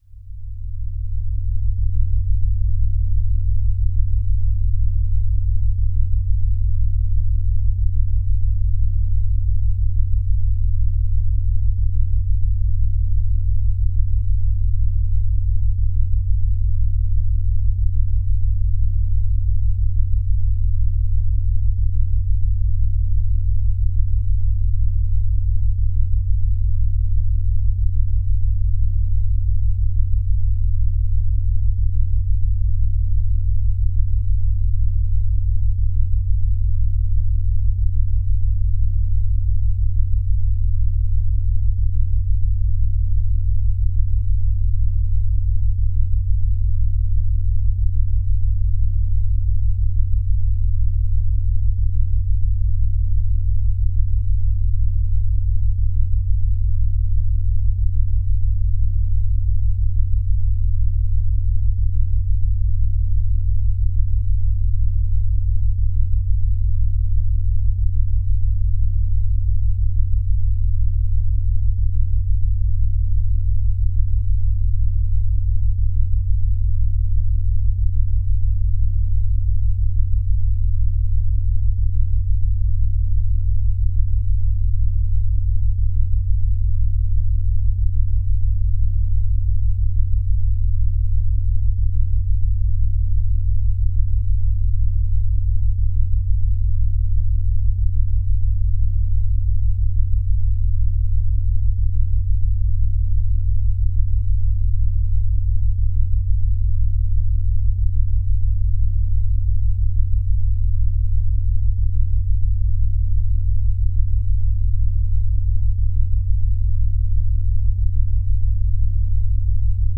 Cette fréquence vibratoire ameliore les processus des anti – inflammatoires
Cette-frequence-vibratoire-ameliore-les-processus-des-anti-inflammatoires.mp3